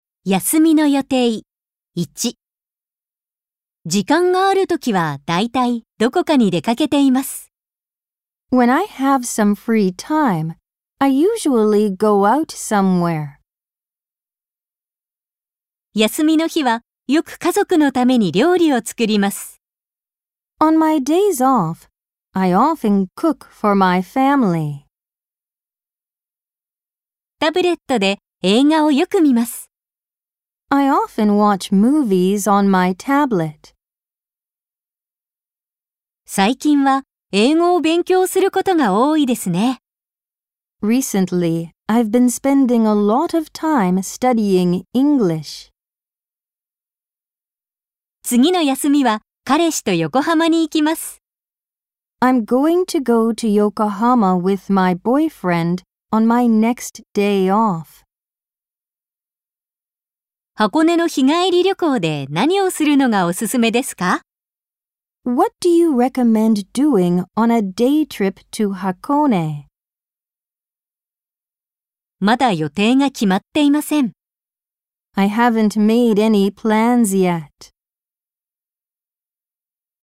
・ナレーター：アメリカ英語のネイティブ（女性）と日本語ナレーター（女性）
・スピード：少しゆっくり／ナチュラル